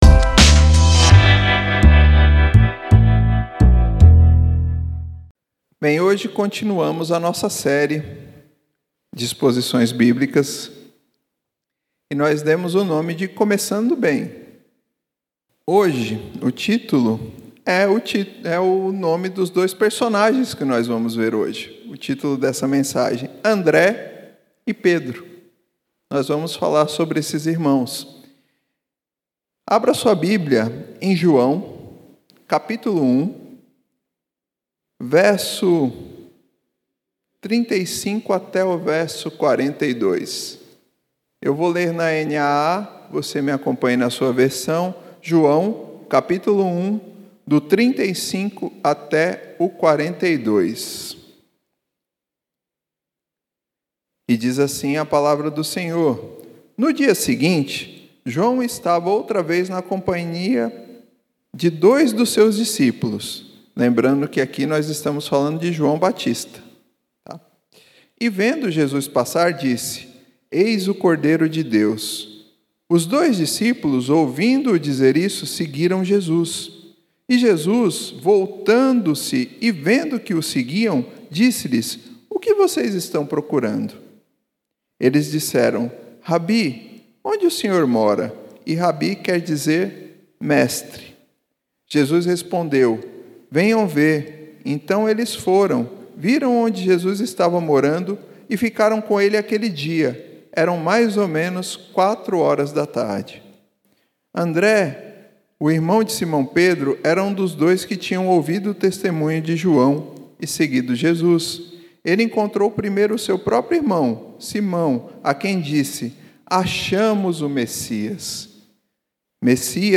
Mensagem realizado nos encontros de Reflexão de Oração às Quintas-Feiras 20h.